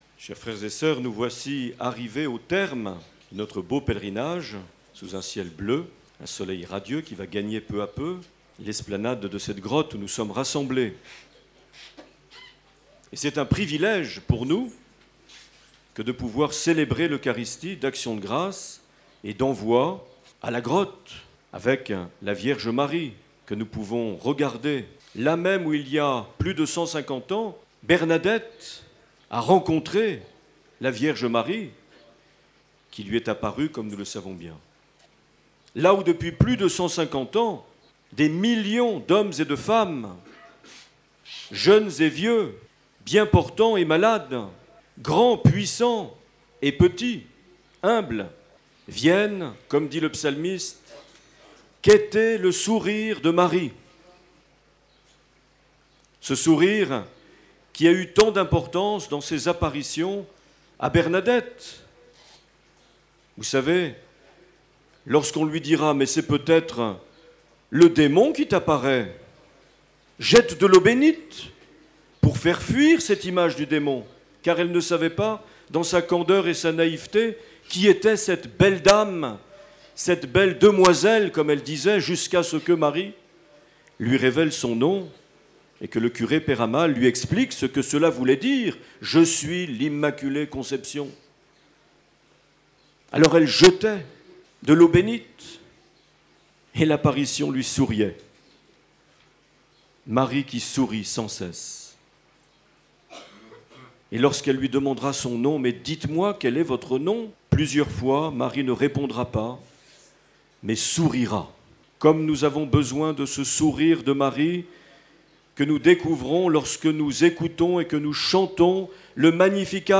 20 septembre 2010 - Lourdes Grotte Massabielle - Messe de clôture du Pèlerinage Diocésain
Accueil \ Emissions \ Vie de l’Eglise \ Evêque \ Les Homélies \ 20 septembre 2010 - Lourdes Grotte Massabielle - Messe de clôture du (...)
Une émission présentée par Monseigneur Marc Aillet